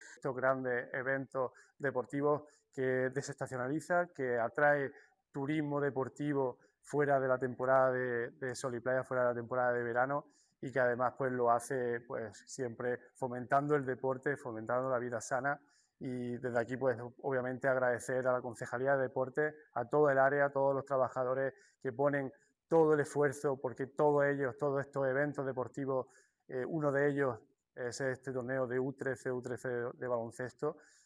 20_04_torneo_baloncesto_vera_alcalde.mp3.mp3